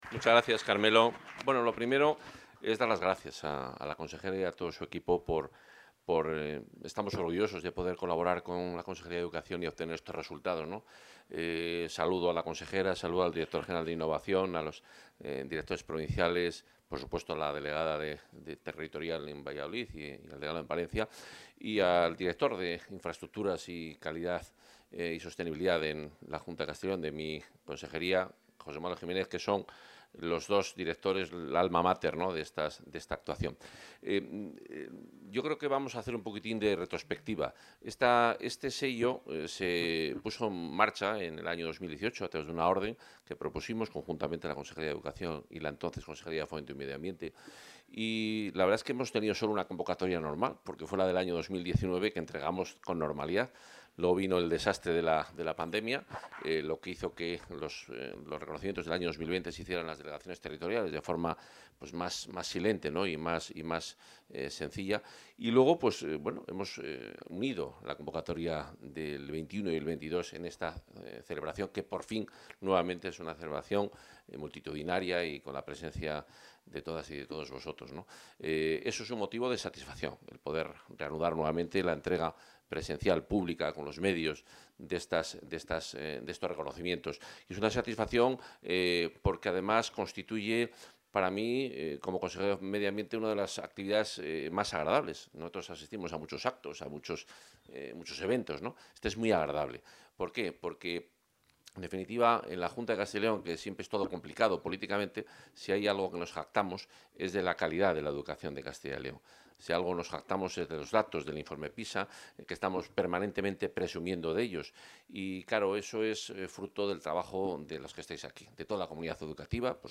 Intervención consejero Medio Ambiente, Vivienda y Ord. del Territorio.